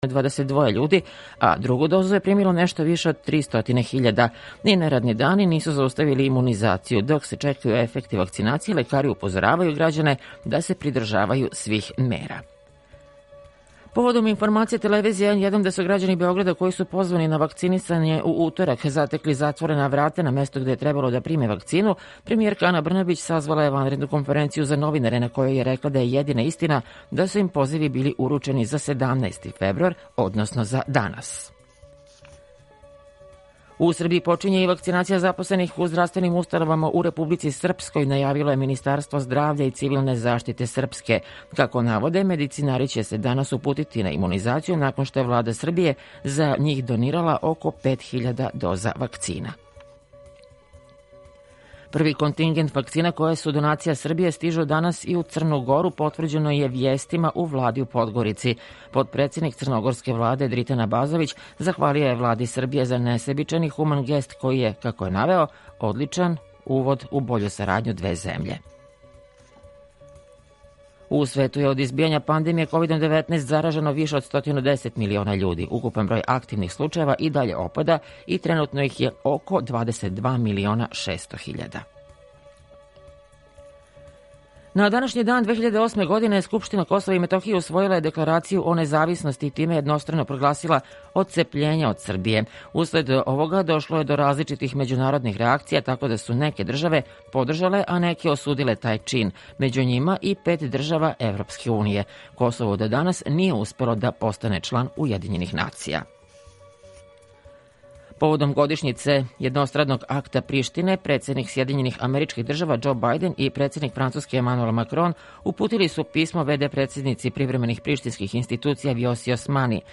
Укључење Радија Косовска Митровица
Јутарњи програм из три студија
У два сата, ту је и добра музика, другачија у односу на остале радио-станице.